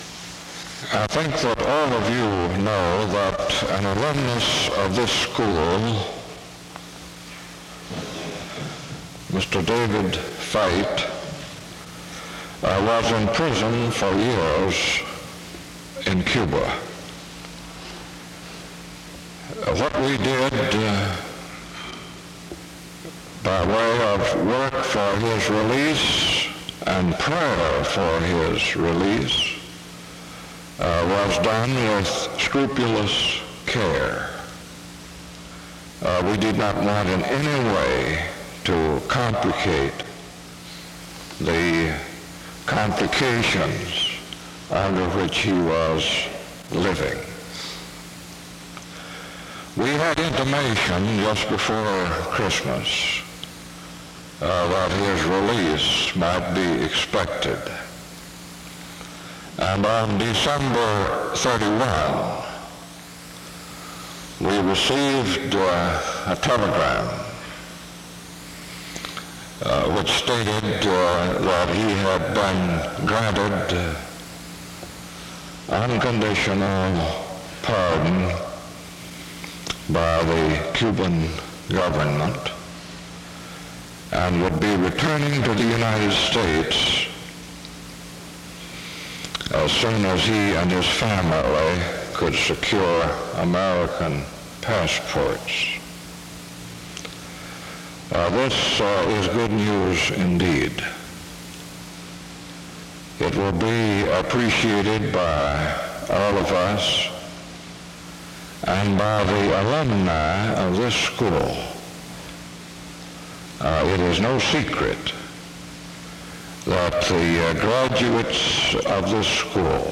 A prayer is offered from 2:47-5:17. Luke 11:1-4 is read from 5:42-7:00.
SEBTS Chapel and Special Event Recordings SEBTS Chapel and Special Event Recordings